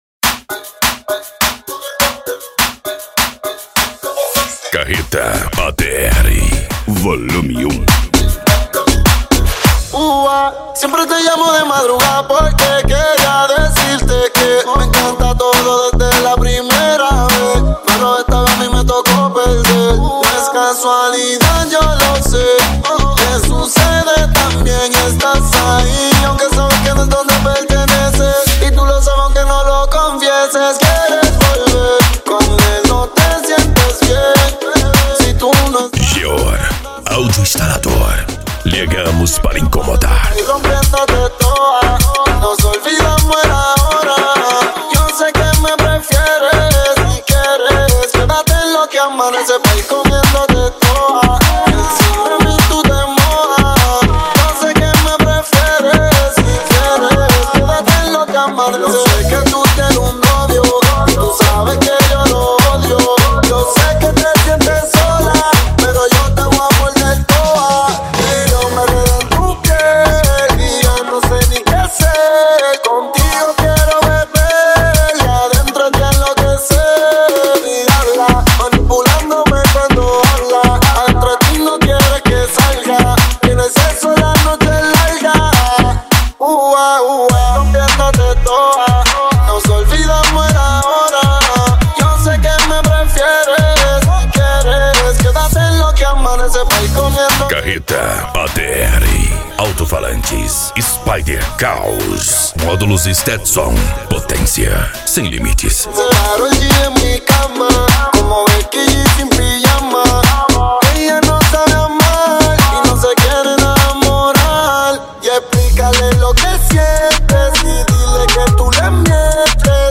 Bass
Remix